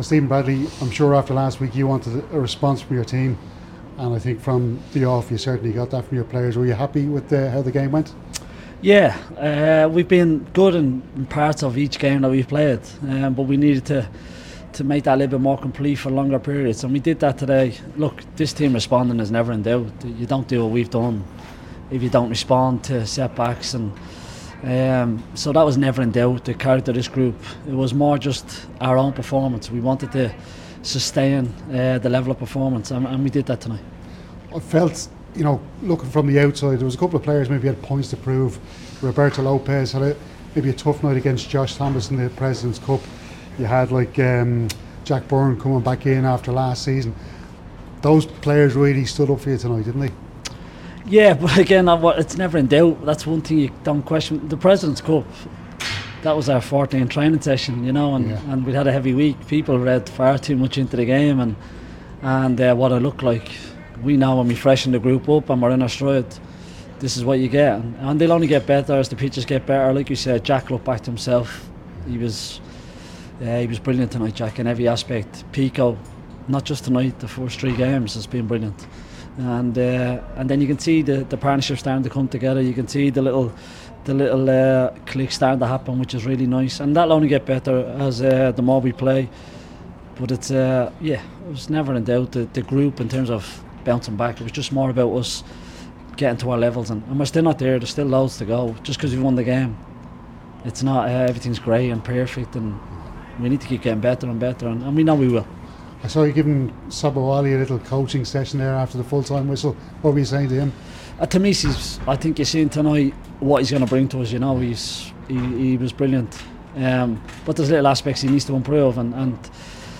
Rovers manager Stephen Bradley says his team are starting to click…